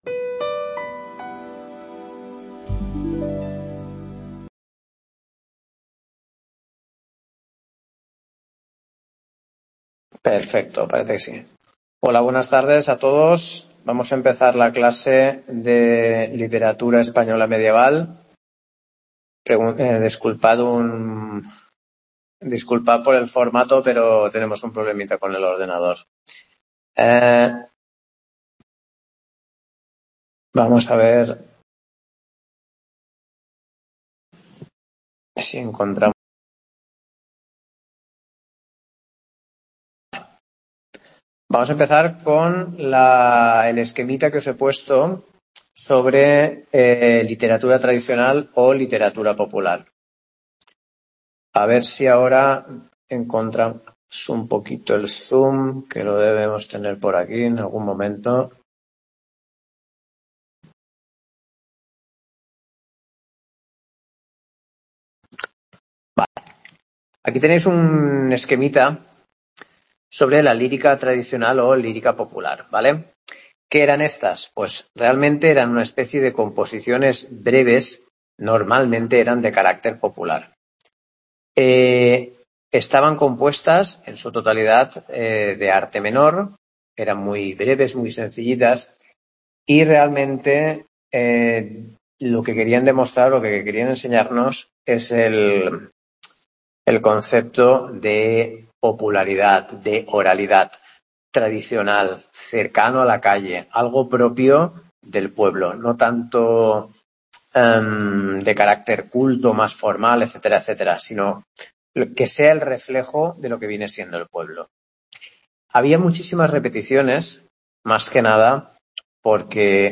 Parte 1, perdón por la calidad, problemas técnicos.